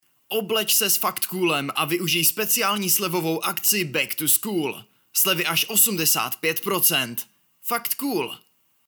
Hlas pro Vaše video! (voiceover)
K dispozici mám profesionální mikrofon a zvuk jsem schopen sám upravit a poté odeslat v nejvyšší možné kvalitě.
ukázka_reklama_factcool.mp3